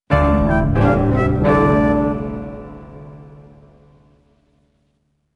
乐器类/重大事件短旋律－宏大